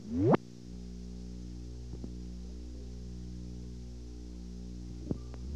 Secret White House Tapes
Location: White House Telephone
The President talked with the White House operator.